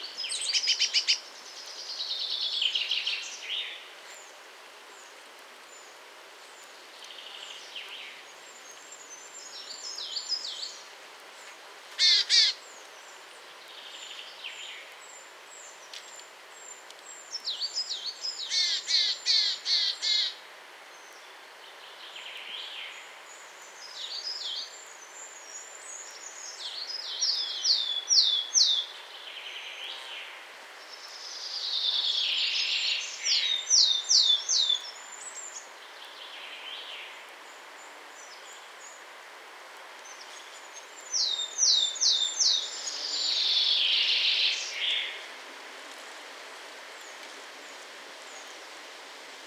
Cincia alpestre
• (Poecile montanus)
Cincia-alpestre.mp3